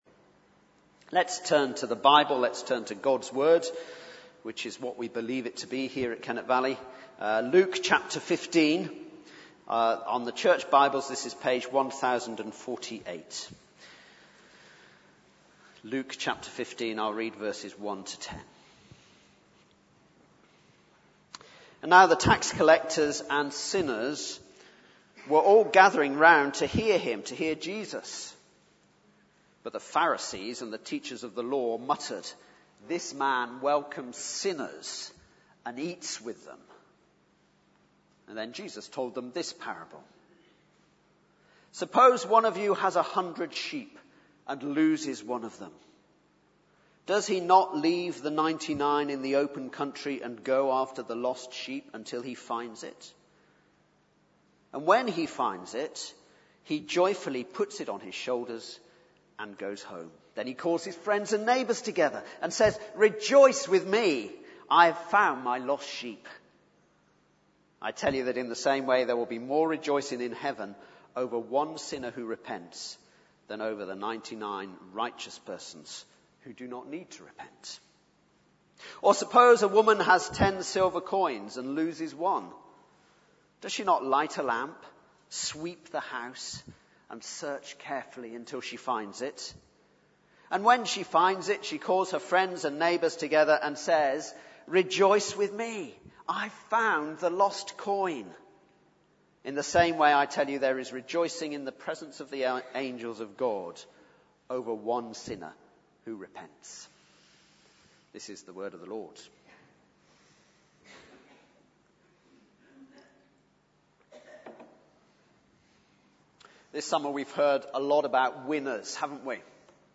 Baptismal Service – September 2012 – Luke 15:1-10